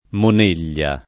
[ mon % l’l’a ]